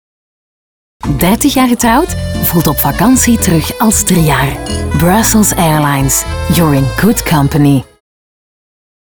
Diep, Jong, Speels, Veelzijdig, Warm
Commercieel